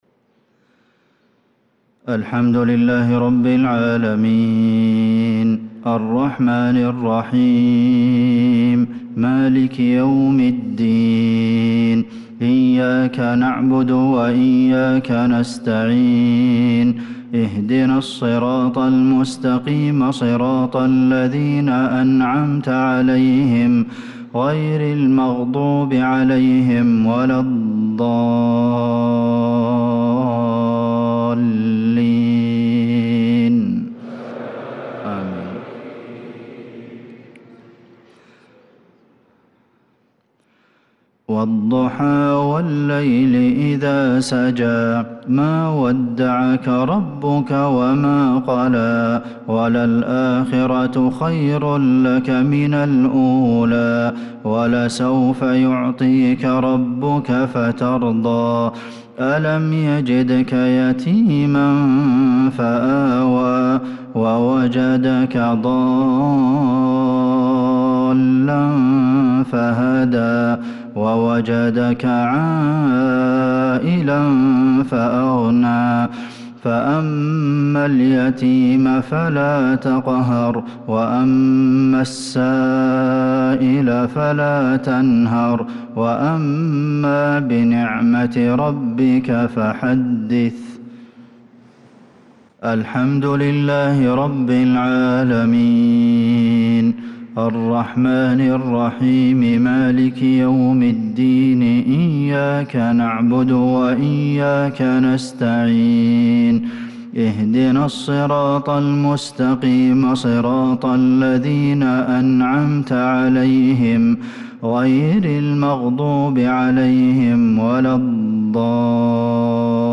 صلاة المغرب للقارئ عبدالمحسن القاسم 8 ذو الحجة 1445 هـ
تِلَاوَات الْحَرَمَيْن .